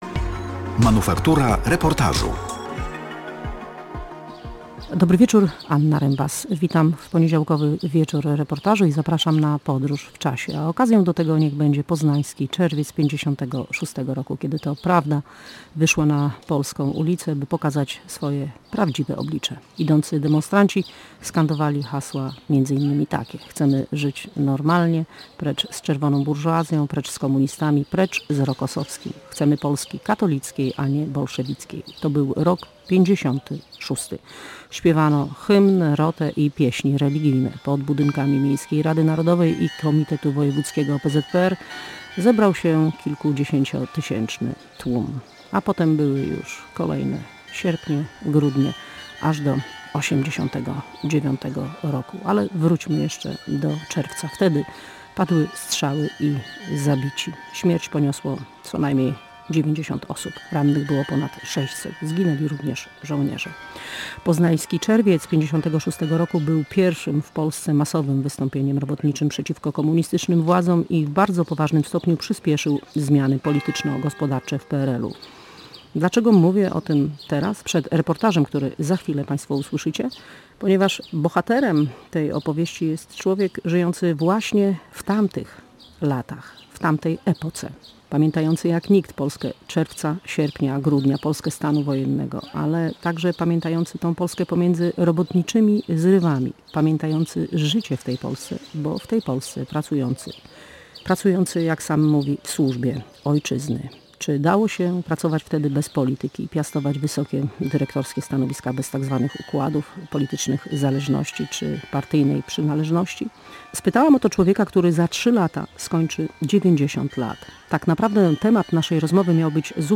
W reportażu nie brakuje też sentymentalnych odniesień i wspomnień.